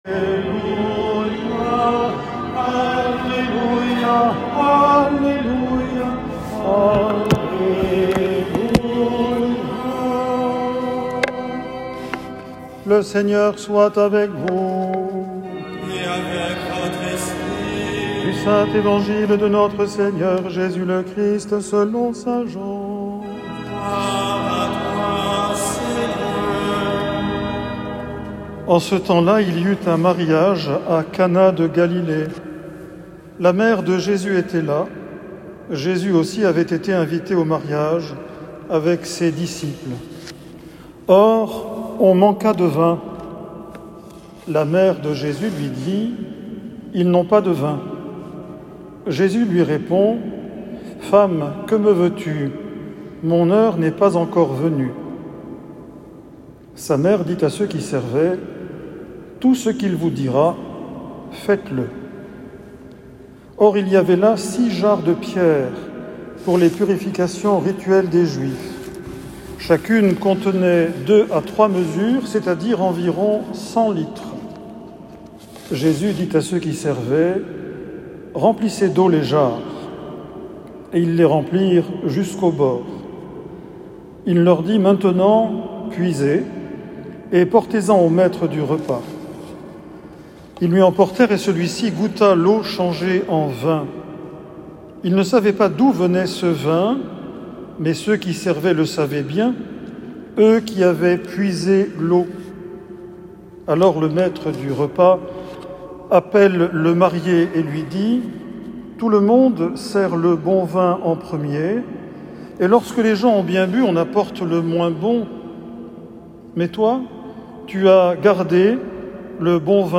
Homélie 2ème dimanche du TO Année C 2025